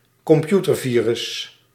Ääntäminen
US : IPA : [ˈvaɪ.rəs]